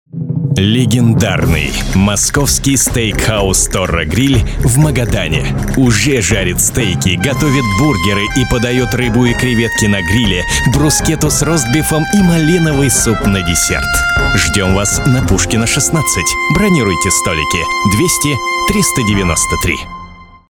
Аудиореклама для Торро Гриль